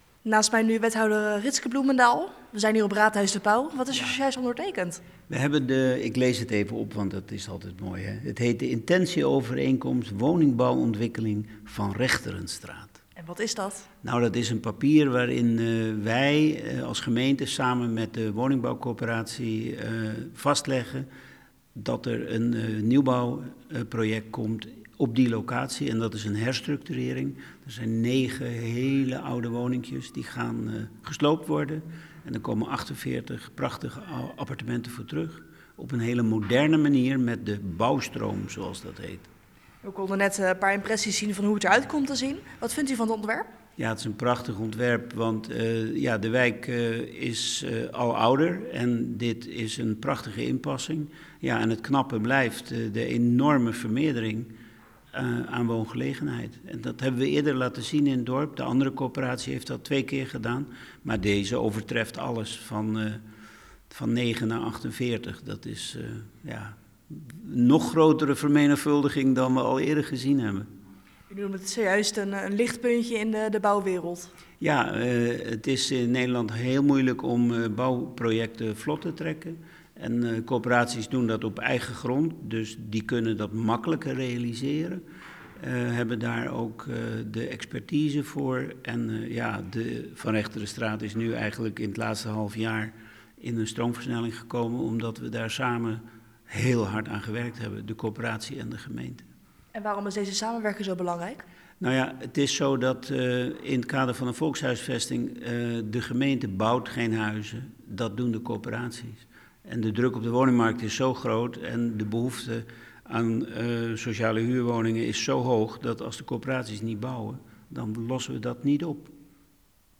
Verslaggever
in gesprek met wethouder Ritske Bloemendaal.